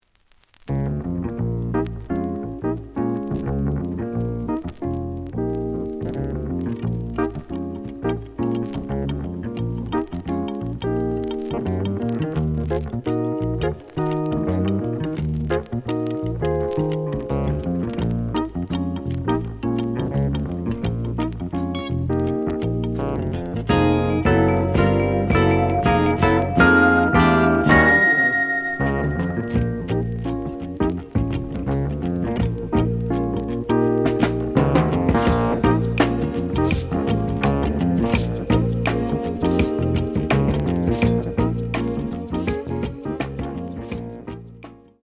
The audio sample is recorded from the actual item.
●Genre: Jazz Rock / Fusion
Looks good, but slight noise on B side.